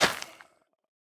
Minecraft Version Minecraft Version snapshot Latest Release | Latest Snapshot snapshot / assets / minecraft / sounds / block / soul_soil / break4.ogg Compare With Compare With Latest Release | Latest Snapshot